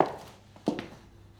Fotsteg - footsteps.scd
footsteps.wav